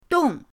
dong4.mp3